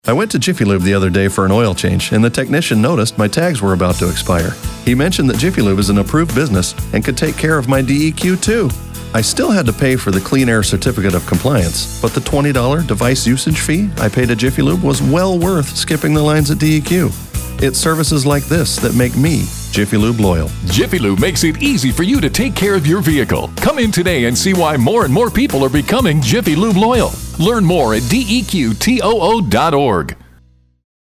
30 second ad